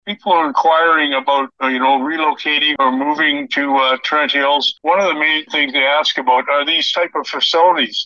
The Mayor says Trent Hills has been experiencing a boom recently.